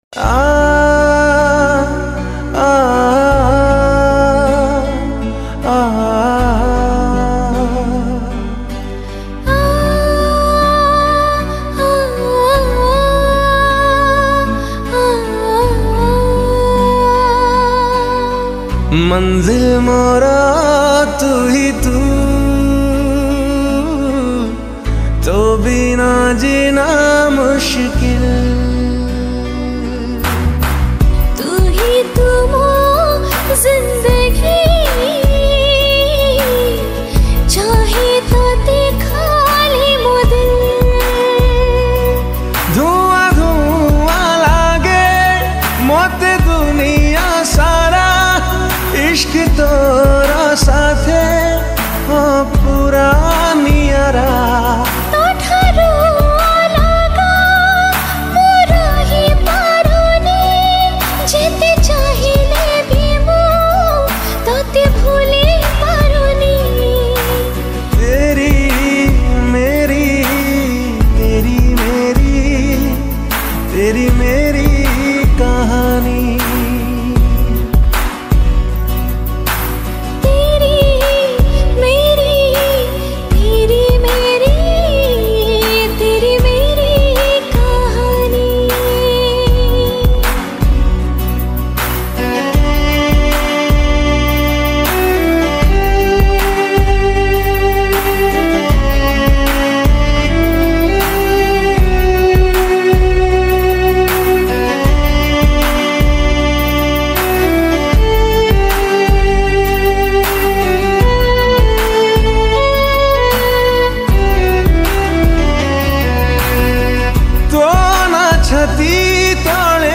New Odia Sad Song